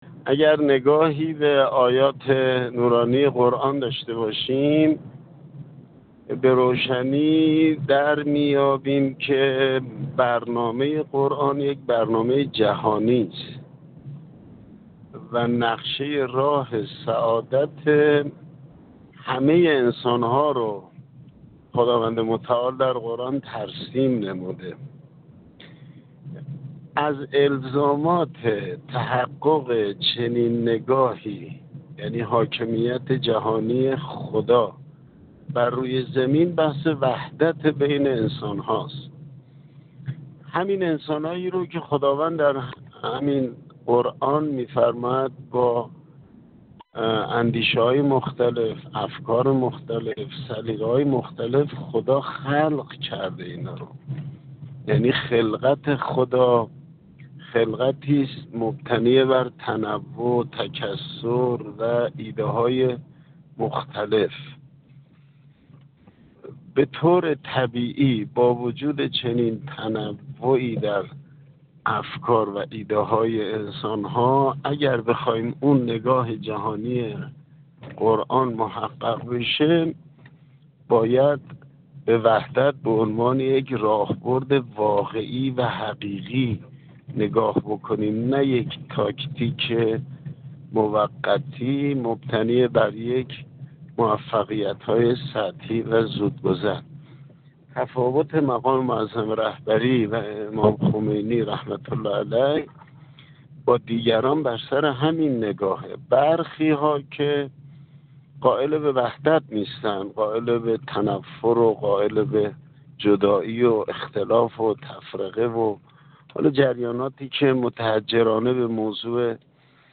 حجت‌الاسلام و المسلمین محمدرضا تویسرکانی، نماینده ولی فقیه در نیروی هوافضای سپاه، در گفت‌وگو با ایکنا درباره مسئله وحدت به منزله لازمه شکل‌گیری قطب قدرت جهان اسلام گفت: اگر نگاهی به آیات نورانی قرآن بیندازیم به روشنی در می‌ِیابیم که برنامه قرآن برای بشریت یک برنامه جهانی است و خداوند متعال نقشه راه سعادت همه انسان‌ها را در قرآن ترسیم کرده است.